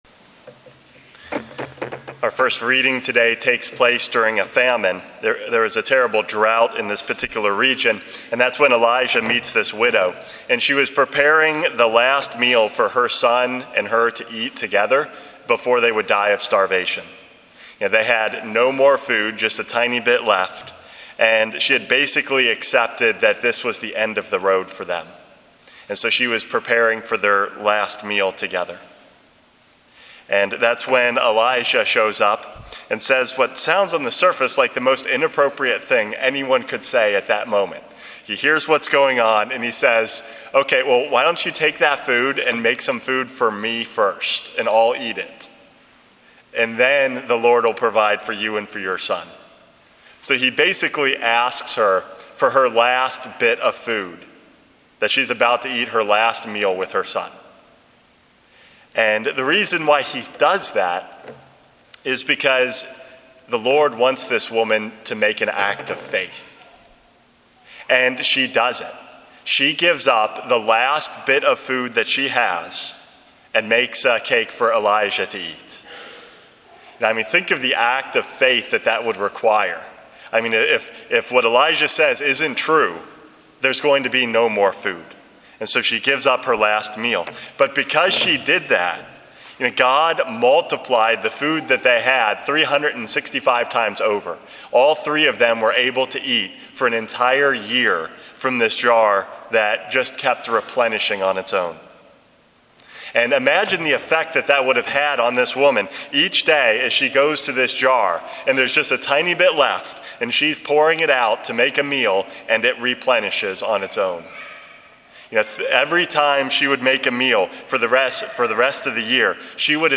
Homily #423 - Giving God the Best